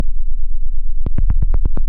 • Techno deep Bass Section.wav
Techno_deep_Bass_Section__eWz.wav